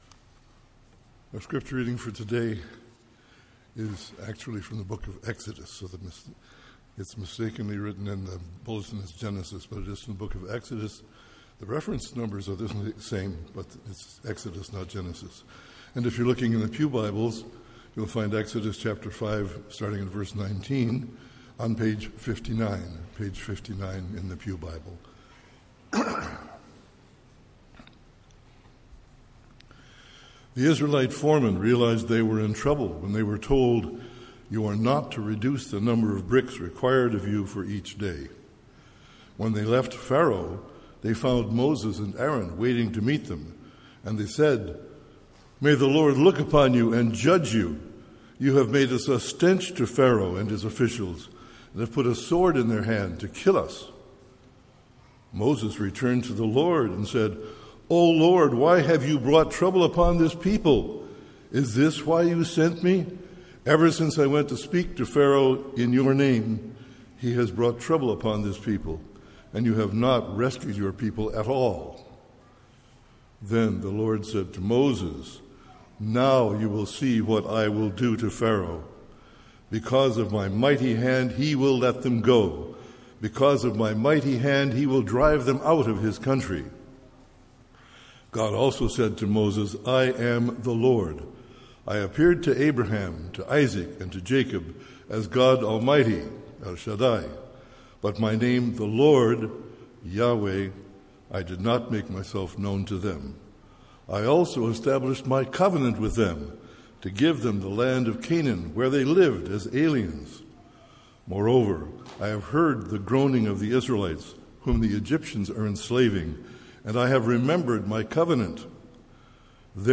MP3 File Size: 26.5 MB Listen to Sermon: Download/Play Sermon MP3